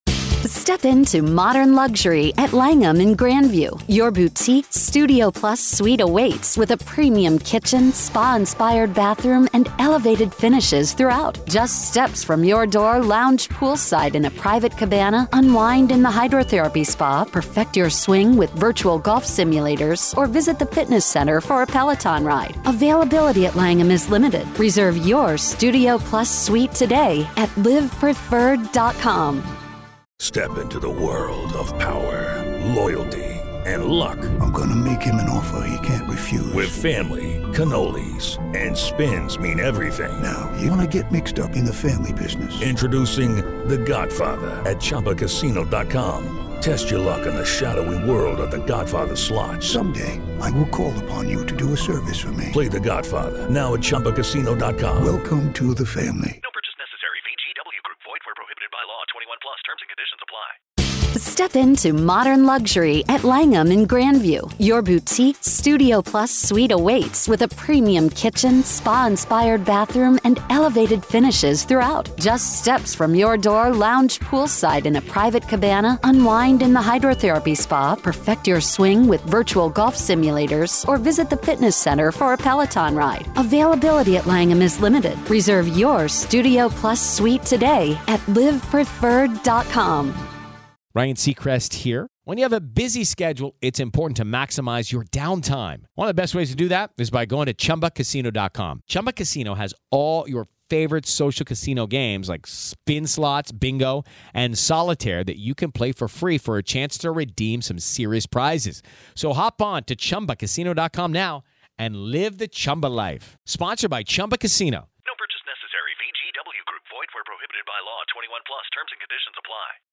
The discussion raises questions about the nature of evil and the psychological justification for heinous acts under the guise of religious or cult beliefs. Main Points of the Conversation - Characteristics that make individuals susceptible to cults, such as emotional neediness and a background in magical thinking.